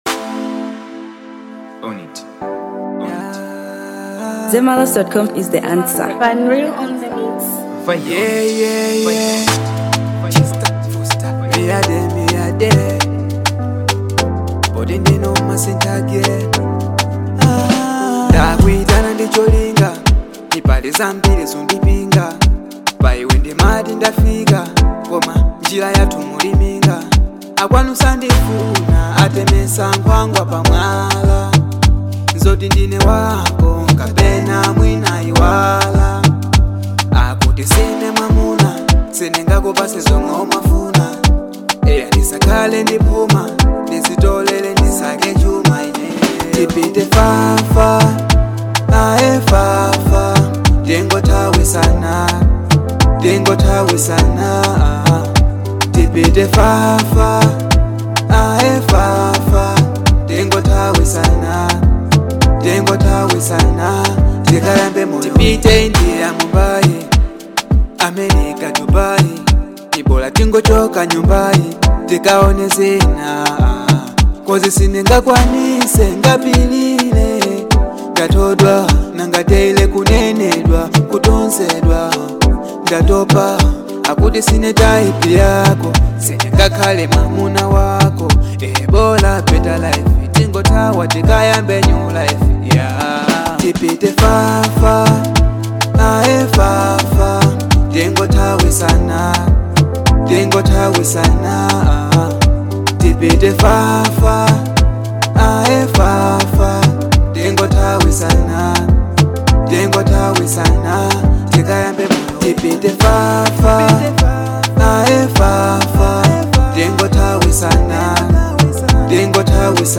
Afrobeats • 2025-09-05